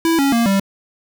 fall.wav